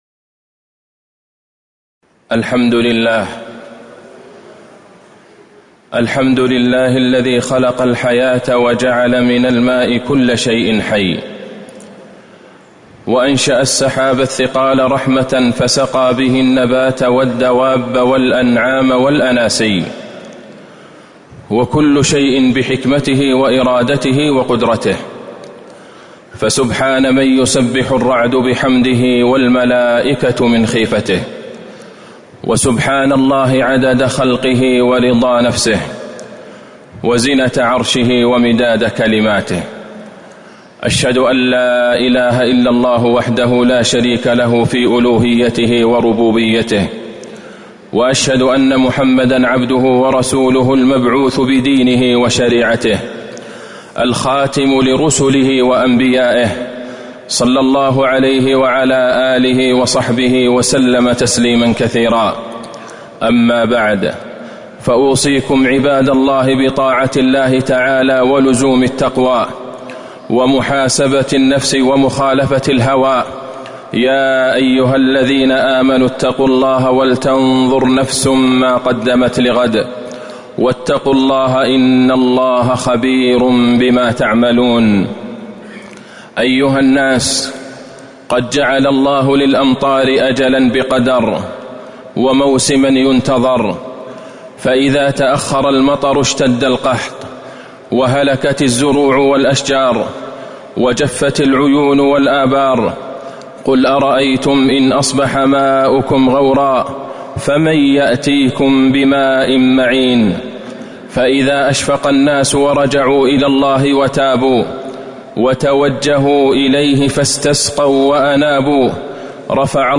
خطبة الاستسقاء - المدينة - الشيخ عبدالله البعيجان - الموقع الرسمي لرئاسة الشؤون الدينية بالمسجد النبوي والمسجد الحرام
المكان: المسجد النبوي